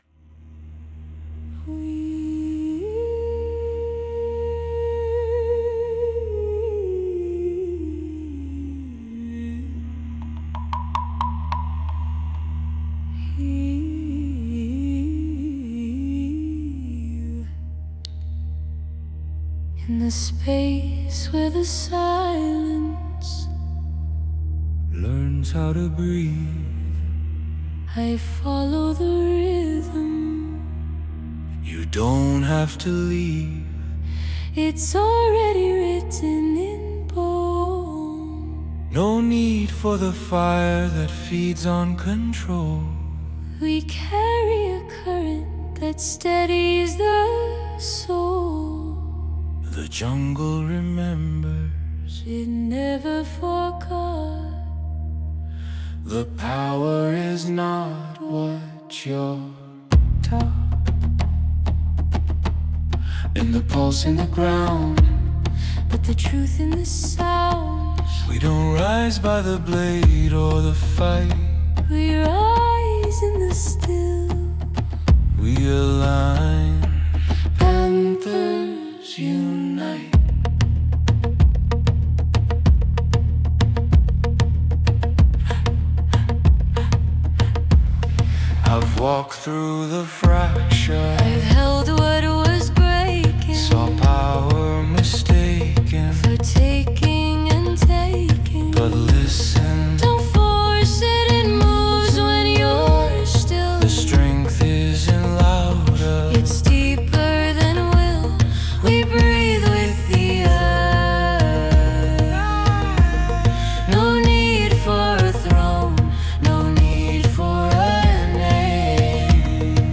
Dramatic,Emotional,Inspirational,Motivational
Dramatic Emotional Inspirational Motivational